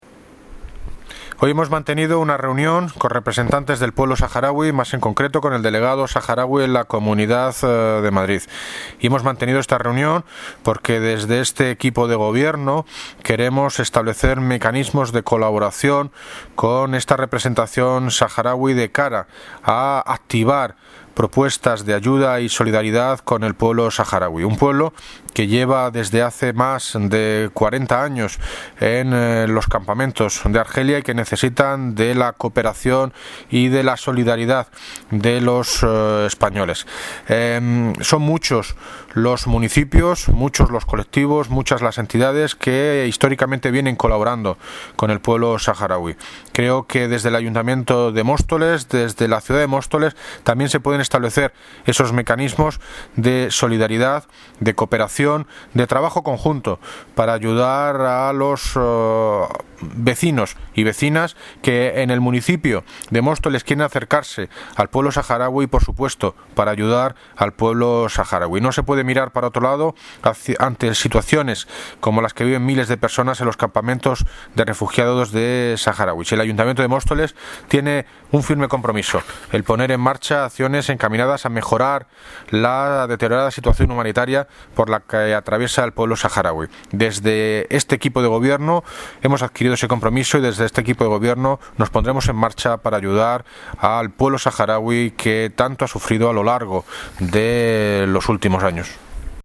Audio - David Lucas (Alcalde de Móstoles) sobre reunión Delegación Sahara en Madrid